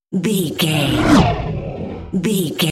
Horror whoosh creature
Sound Effects
Atonal
scary
ominous
haunting
eerie
whoosh